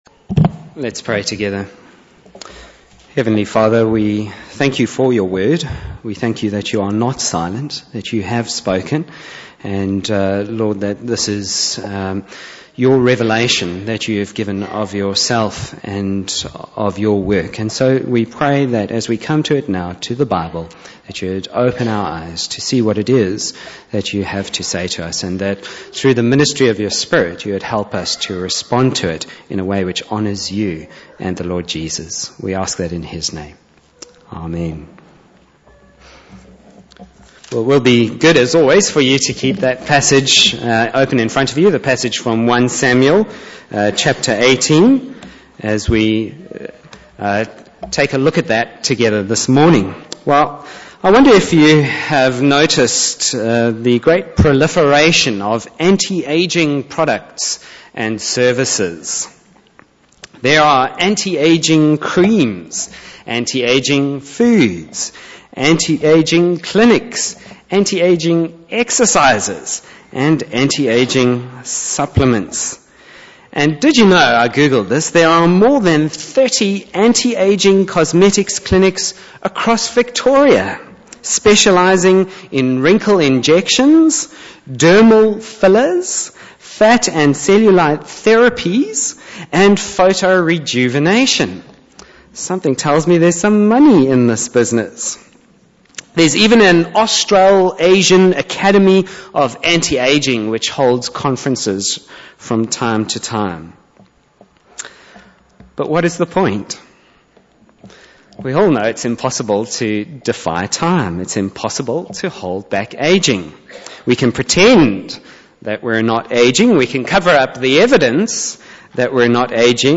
Bible Text: 1 Samuel 18:1-16 | Preacher